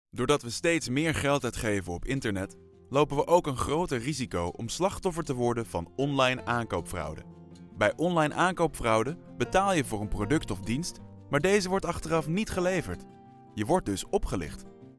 Sprechprobe: eLearning (Muttersprache):
Fluent in both Dutch and English, and with a wide range of voice, from a warm low to an energetic high.